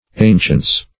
ancients - definition of ancients - synonyms, pronunciation, spelling from Free Dictionary